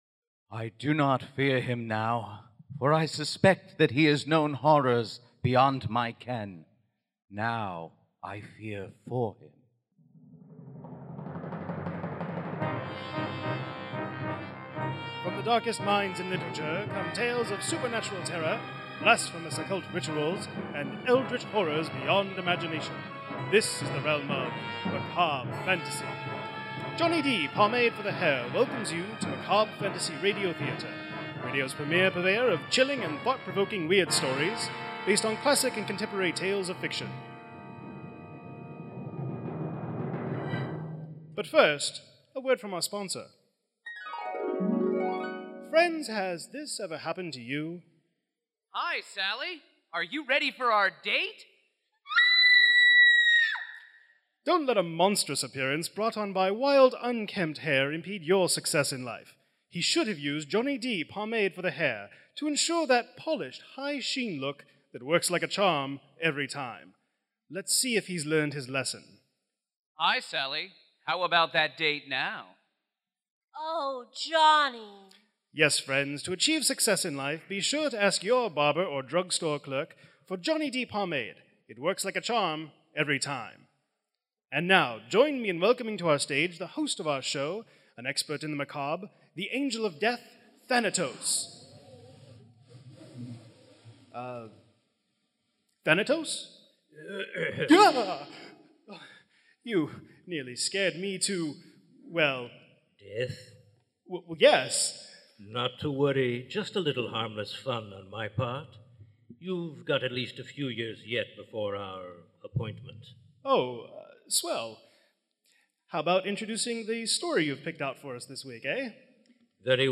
Original adaptations of weird tales/horror in the style of "Old Time Radio"